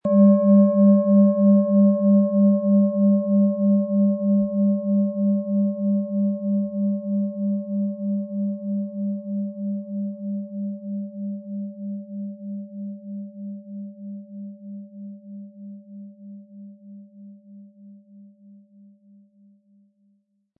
Tibetische Universal-Klangschale, Ø 15,3 cm, 400-500 Gramm, mit Klöppel
Wir haben ebendiese Klangschale beim Aufnehmen getestet und den subjektiven Eindruck, dass sie alle Körperregionen ähnlich deutlich zum Schwingen bringt.
Um den Originalton der Schale anzuhören, gehen Sie bitte zu unserer Klangaufnahme unter dem Produktbild.
SchalenformBihar
MaterialBronze